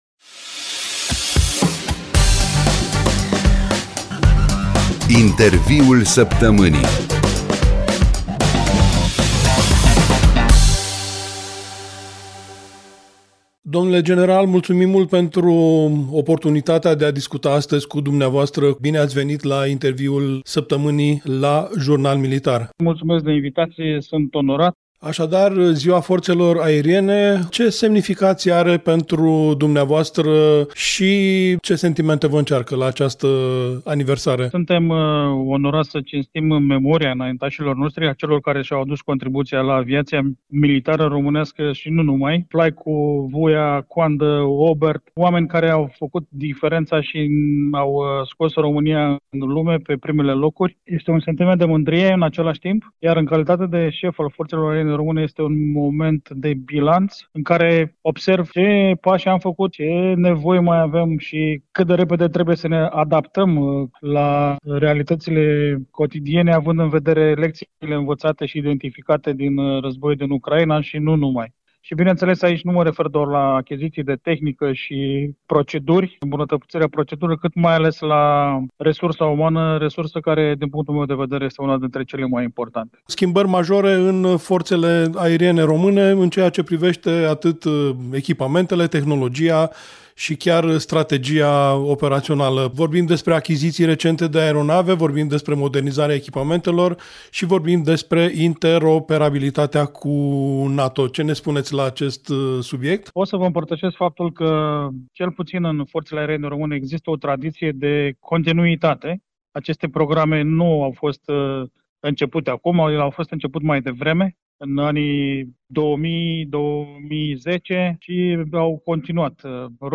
Interviul Săptămânii